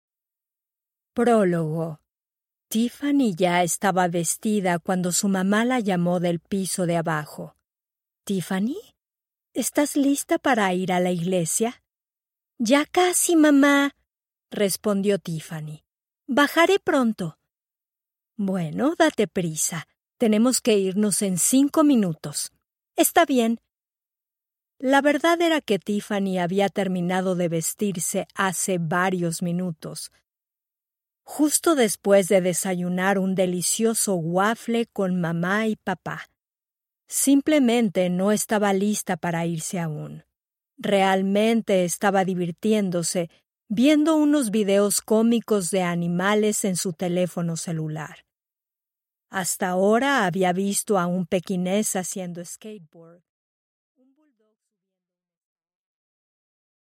Аудиокнига Una Vez Abandonado | Библиотека аудиокниг